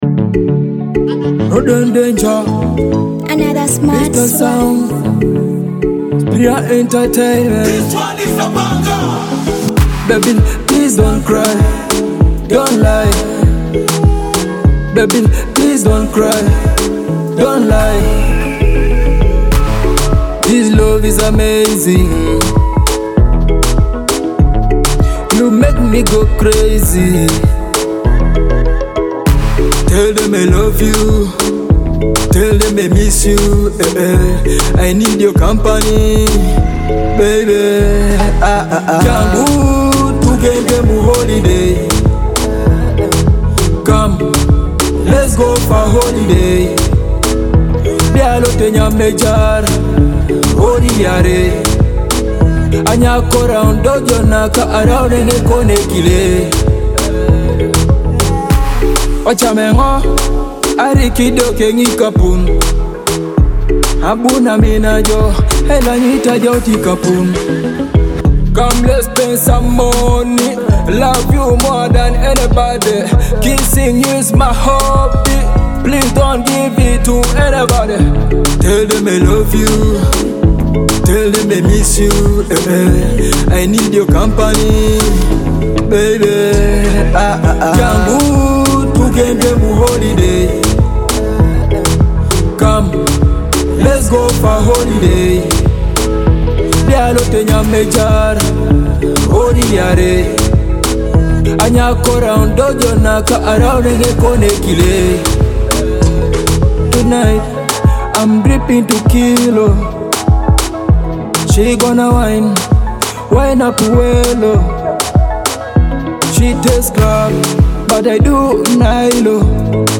sets the tone with its tropical vibes and upbeat tempo